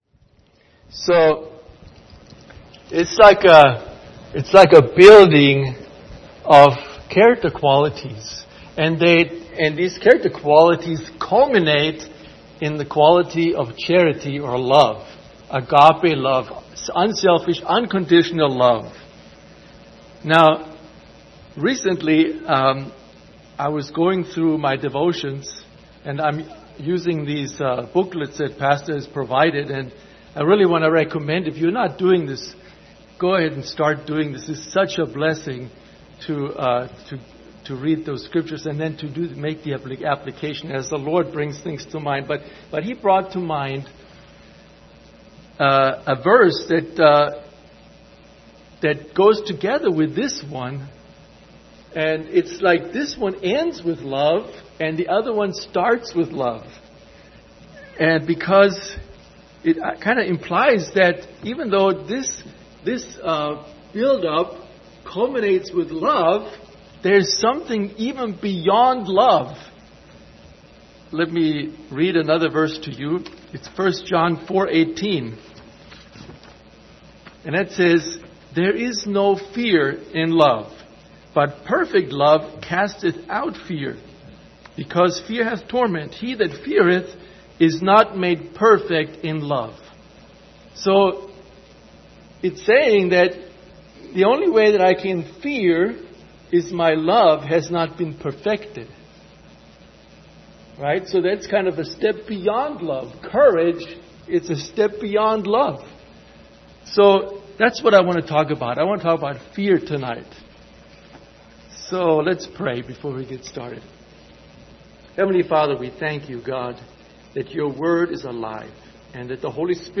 General Passage: 2 Peter 1:5-7 Service Type: Wednesday Evening Topics